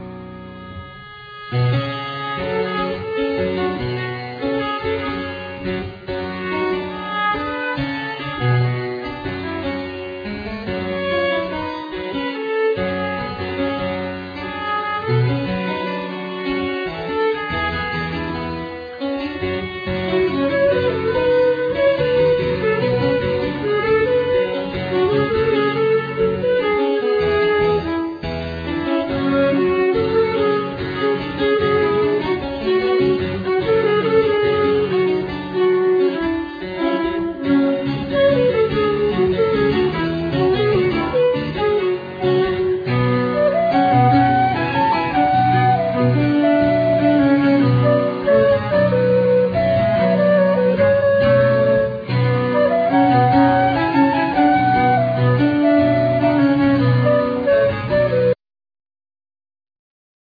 Compositions,Tuba,Saqueboute,Trombone,Voice
Flute,Traverso,Voice
Harpsichord
Viola da gamba
Percussions